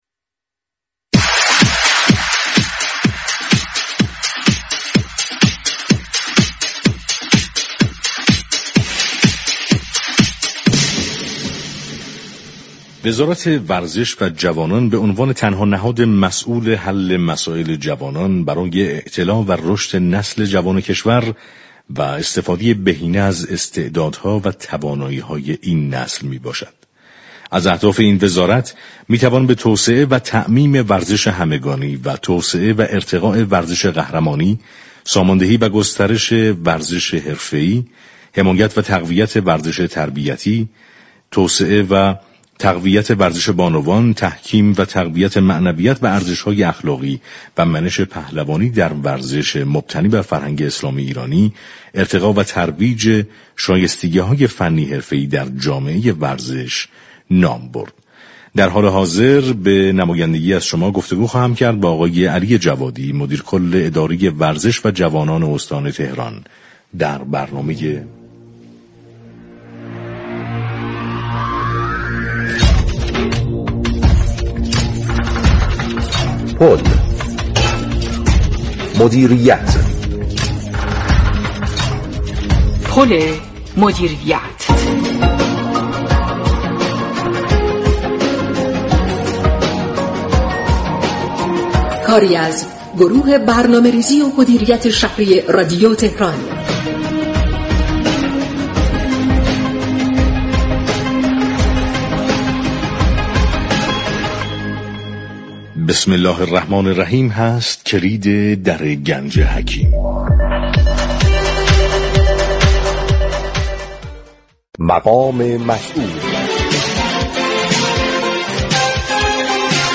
به گزارش پایگاه اطلاع رسانی رادیو تهران، علی جوادی مدیركل اداره ورزش و جوانان استان تهران به مناسبت هفته تربیت بدنی با برنامه پل مدیریت اول آبان گفت و گو كرد.